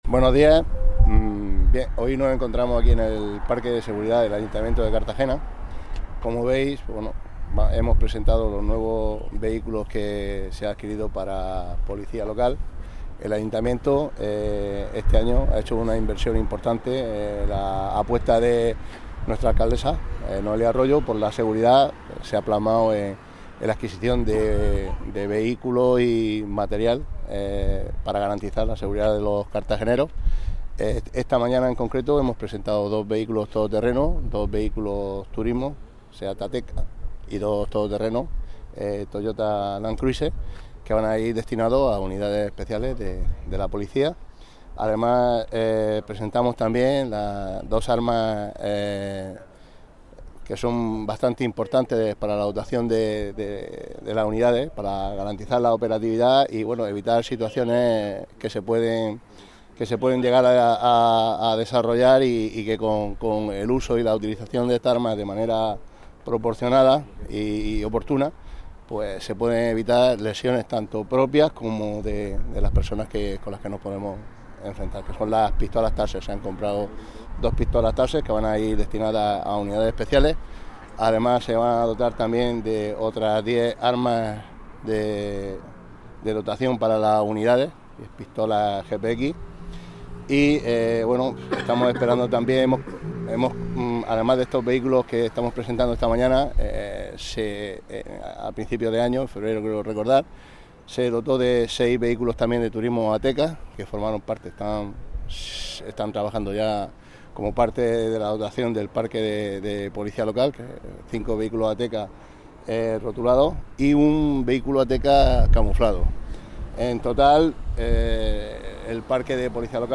Enlace a Declaraciones de José Ramón Llorca sobre adquisición de nuevos medios para Policía Local